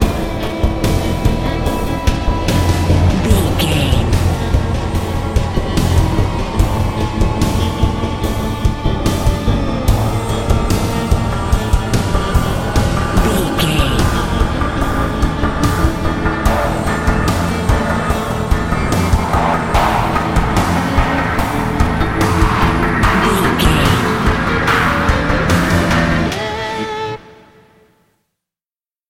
Thriller
Aeolian/Minor
synthesiser
drum machine
electric guitar